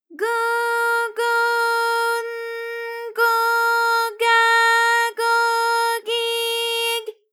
ALYS-DB-001-JPN - First Japanese UTAU vocal library of ALYS.
go_go_n_go_ga_go_gi_g.wav